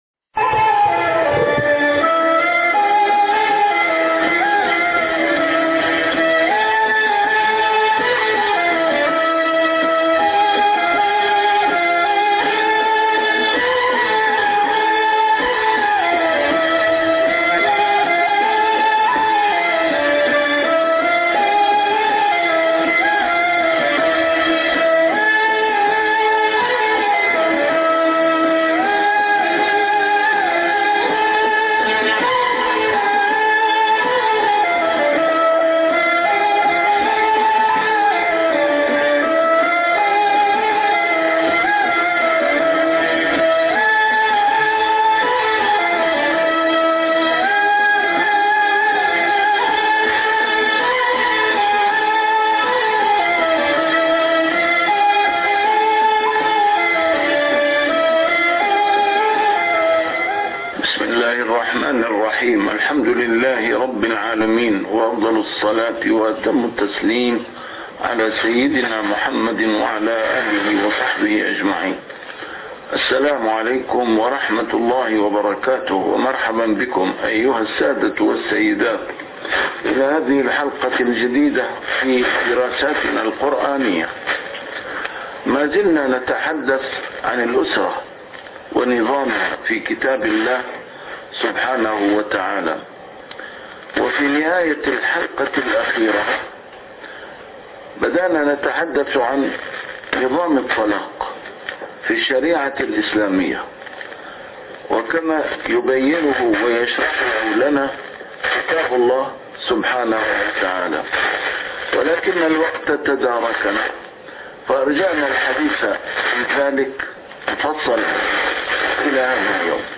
A MARTYR SCHOLAR: IMAM MUHAMMAD SAEED RAMADAN AL-BOUTI - الدروس العلمية - درسات قرآنية الجزء الثاني - نظام الطلاق